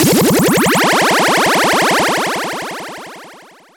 SI2 SPLISH.wav